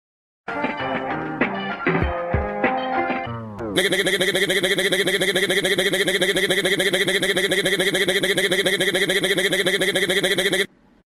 Ni Ni Ni Ni (funny) Sound Effect sound effects free download